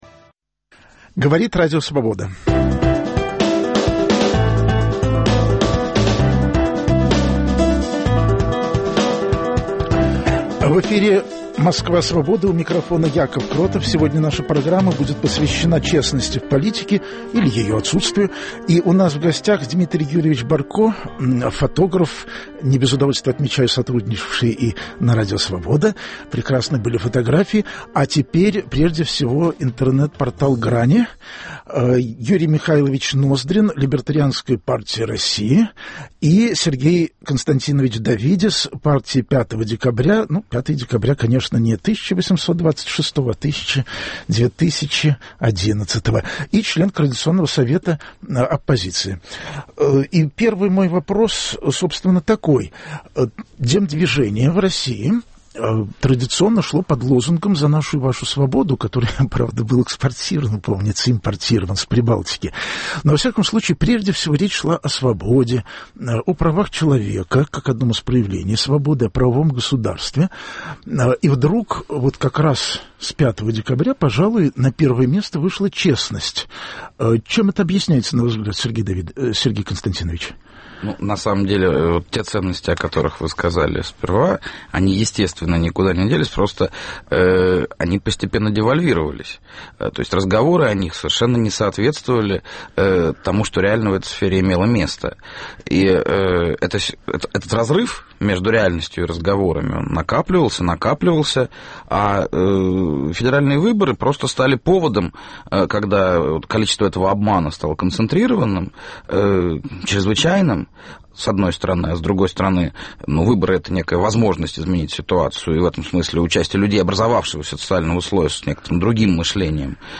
Круглый стол: Москва Свободы